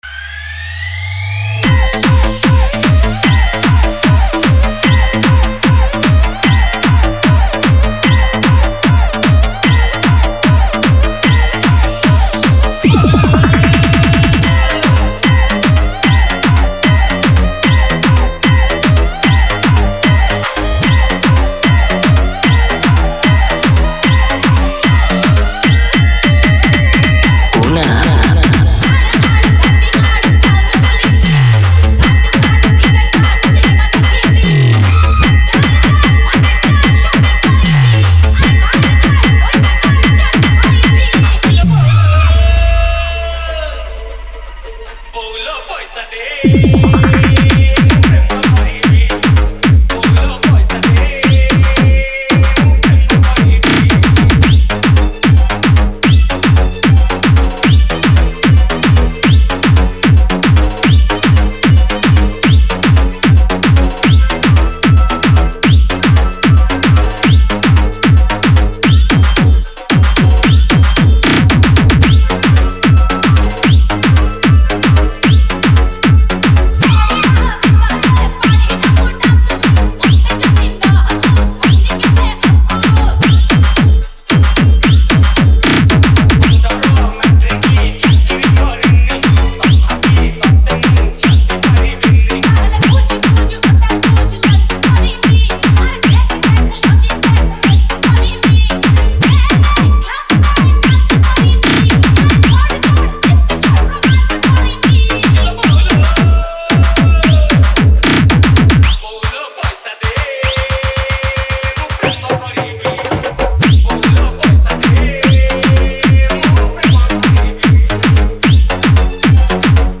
Single Dj Song Collection 2022 Songs Download